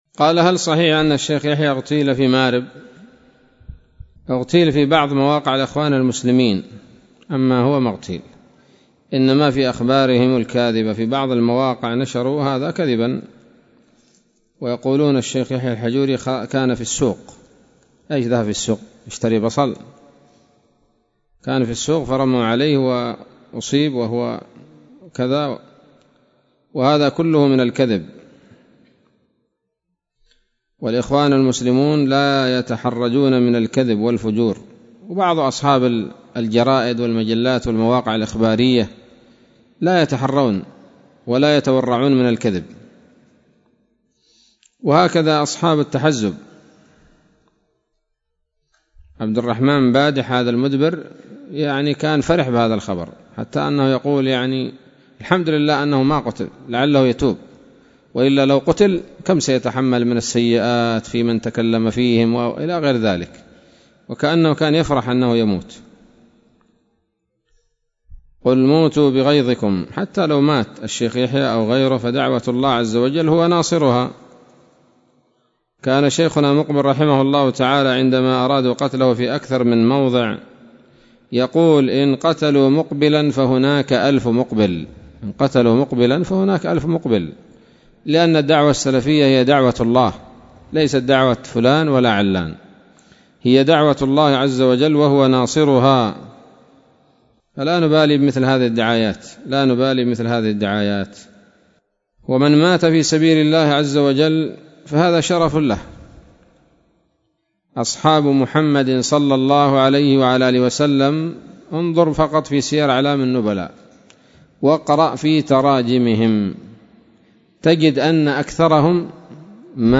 كلمة بعنوان: (( هل صحيح أن الشيخ يحيى الحجوري اغتيل في مأرب؟ )) ليلة الثلاثاء 21 من شهر ذي الحجة لعام 1441 هـ، بدار الحديث السلفية بصلاح الدين